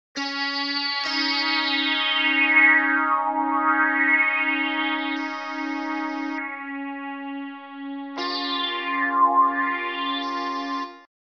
an example of the Equal Temperament scale of SFluidly.